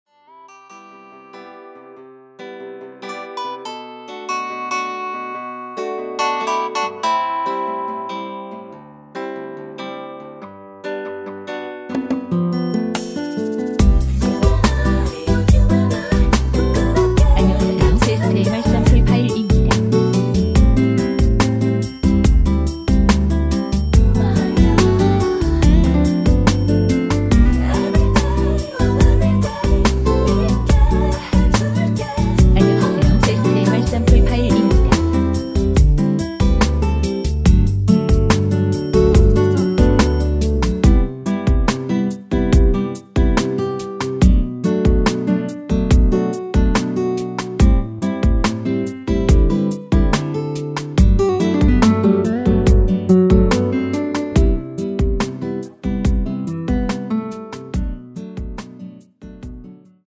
미리듣기에서 나오는 부분이 이곡의 코러스 전부 입니다.(원곡에 코러스가 다른 부분은 없습니다.)
원키에서(-2)내린 코러스 포함된 MR입니다.(미리듣기 확인)
앞부분30초, 뒷부분30초씩 편집해서 올려 드리고 있습니다.
중간에 음이 끈어지고 다시 나오는 이유는